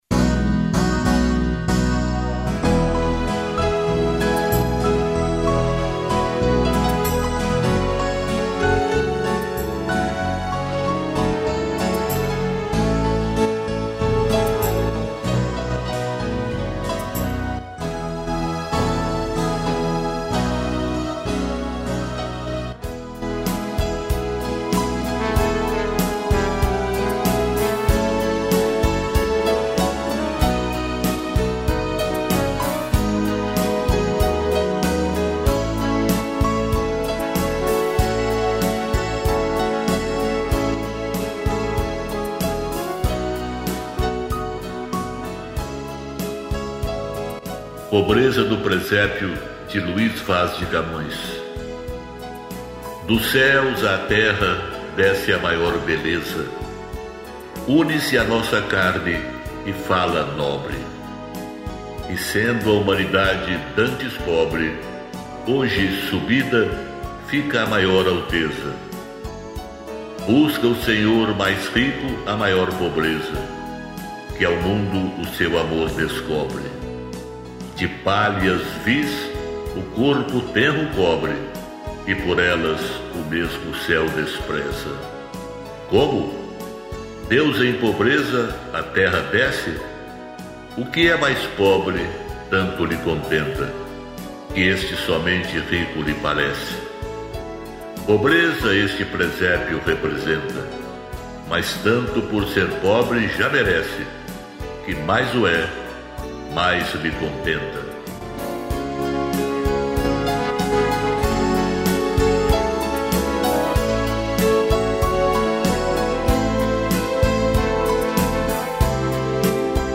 piano e tutti